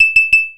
generic_fail.wav